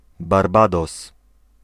Ääntäminen
Vaihtoehtoiset kirjoitusmuodot (vanhahtava) Barbadoes Ääntäminen US UK : IPA : /bɑː(ɹ)ˈbeɪ.dɒs/ US : IPA : /bɑɹˈbeɪ.doʊs/ Lyhenteet ja supistumat (laki) Barb. Haettu sana löytyi näillä lähdekielillä: englanti Käännös Ääninäyte Erisnimet 1.